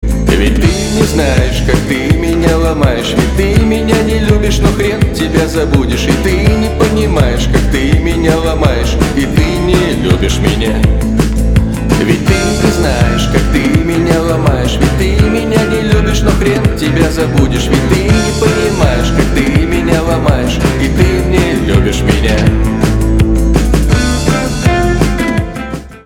пост-панк
грустные